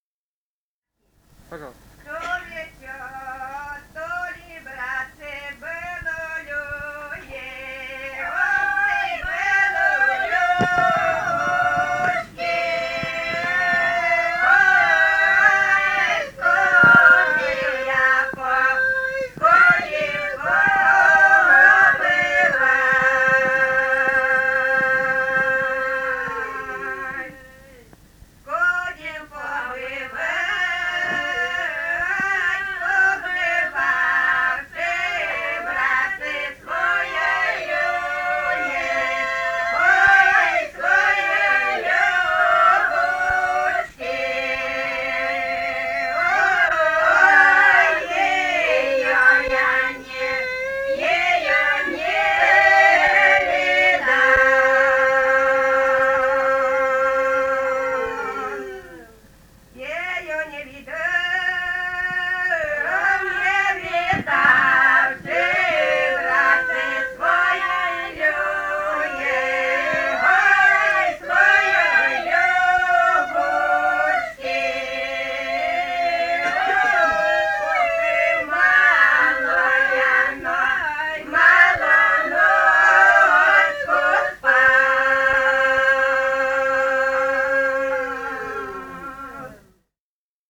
полевые материалы
Костромская область, с. Воскресенское Островского района, 1964 г. И0788-26